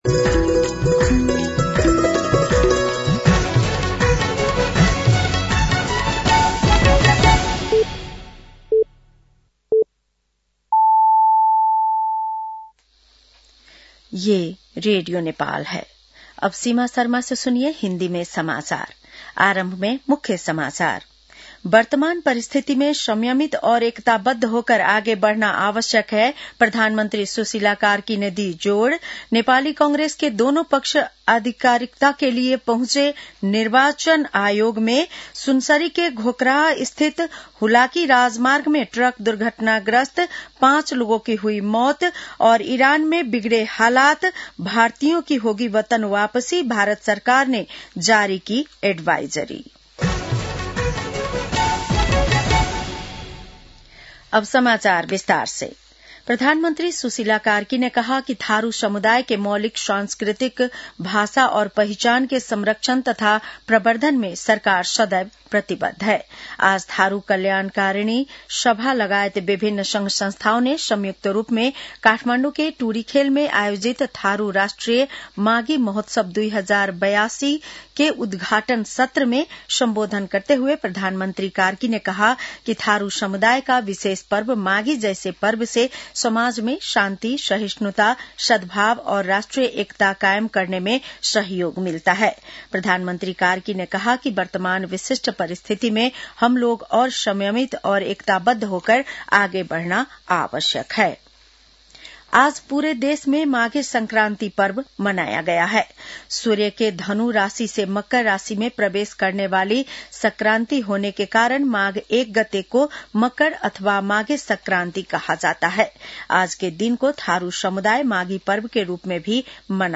बेलुकी १० बजेको हिन्दी समाचार : १ माघ , २०८२
10-PM-Hindi-NEWS-.mp3